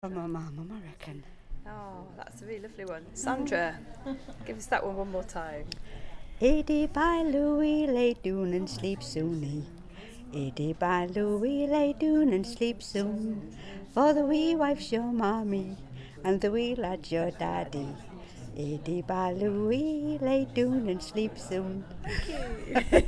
file details Lullaby_recording 2024-10-23 Public Télécharger